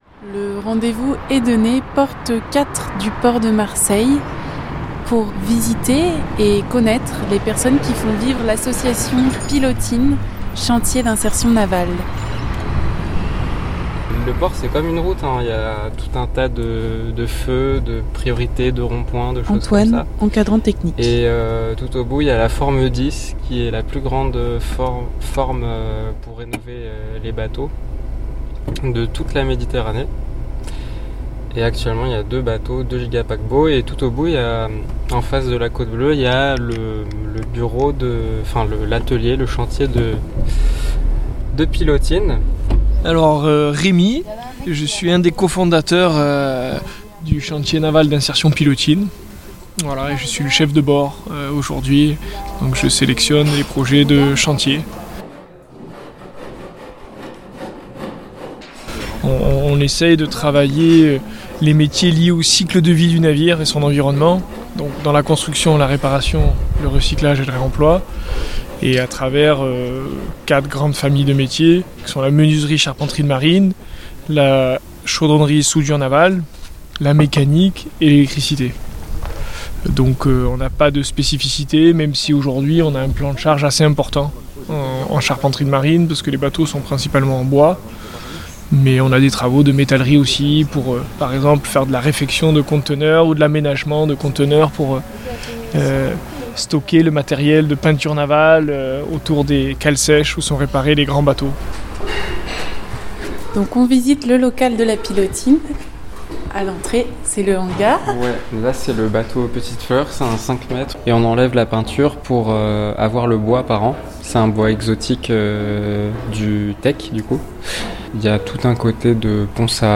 Pilotine, chantier naval d'insertion.mp3 (6.71 Mo) Pilotine est un chantier naval d'insertion spécialisé dans les métiers liés au cycle de vie du navire et à son environnement. Les salariés, les encadrants techniques et le bureau de l'association travaillent au sein du Grand Port Maritime de Marseille et racontent leurs chantiers en cours. Une immersion sonore dans leur univers de travail.